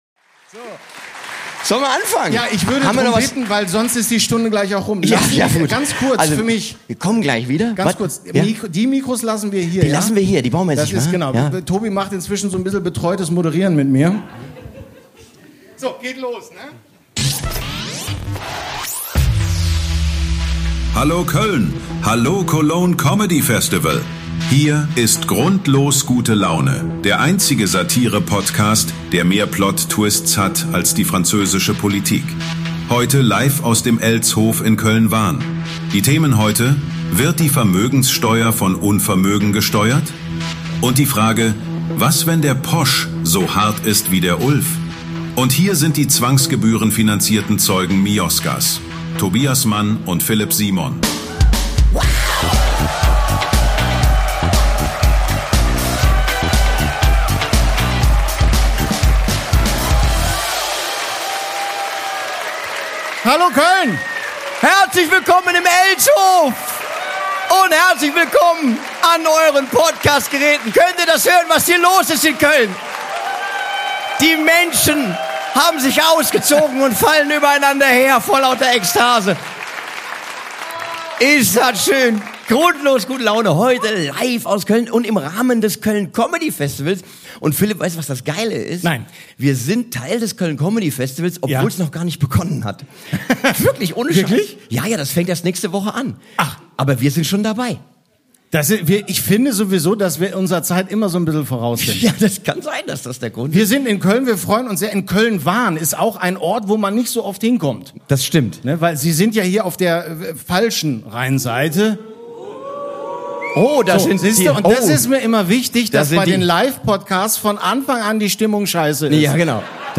Das Geld war alle (LIVE AUS KÖLN) ~ GRUNDLOS GUTE LAUNE Podcast